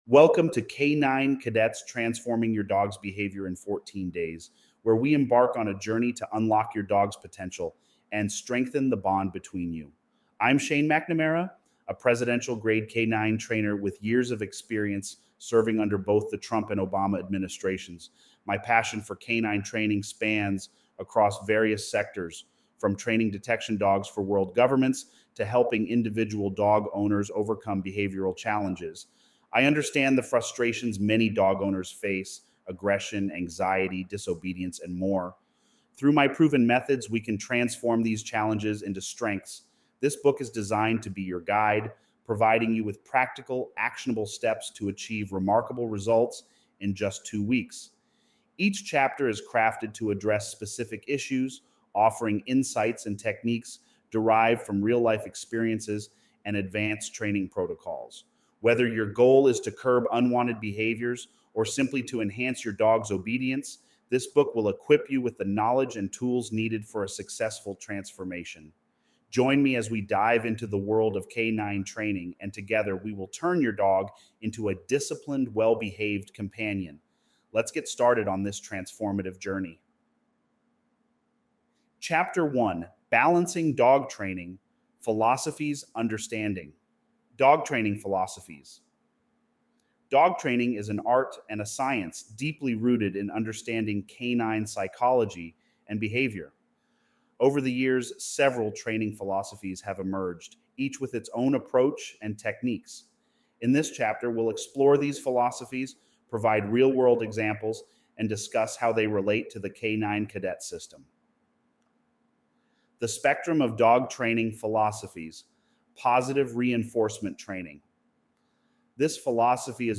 We are excited to offer you a free audio ebook that provides expert dog training tips with a balanced approach.